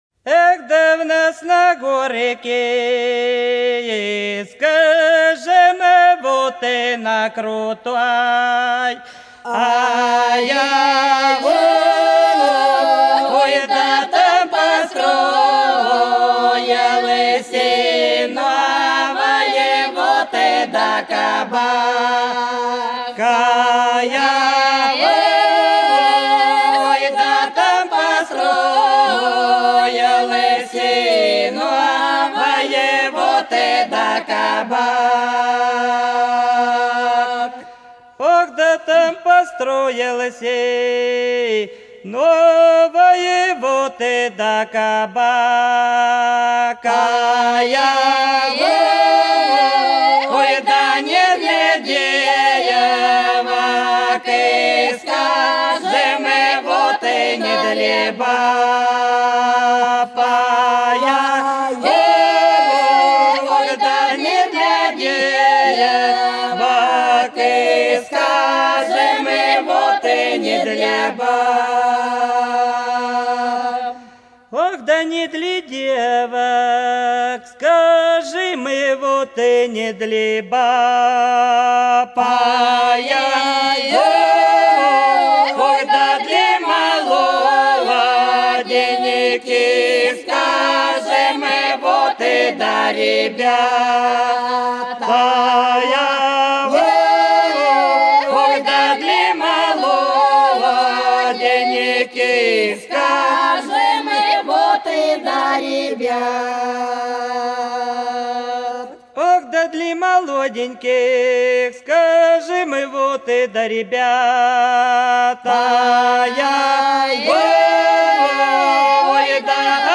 Хороша наша деревня В нас на горке на крутой - протяжная (с. Афанасьевка)
13_В_нас_на_горке_на_крутой_-_протяжная.mp3